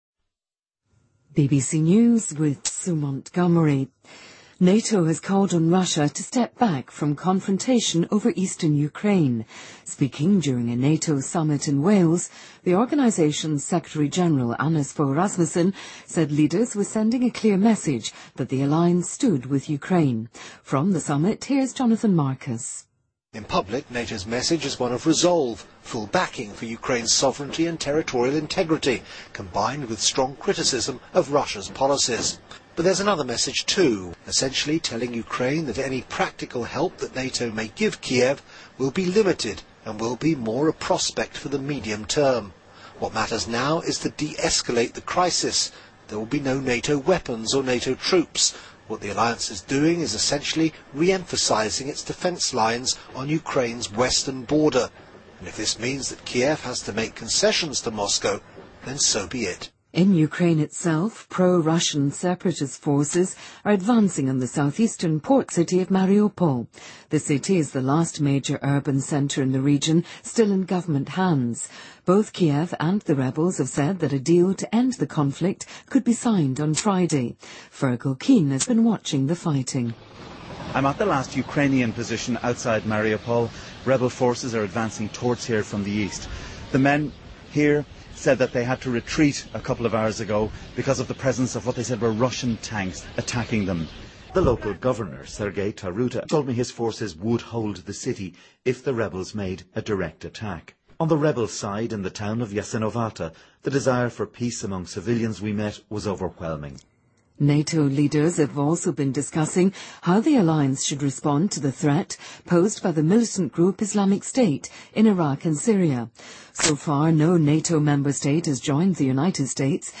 BBC news,英国石油公司被判严重疏忽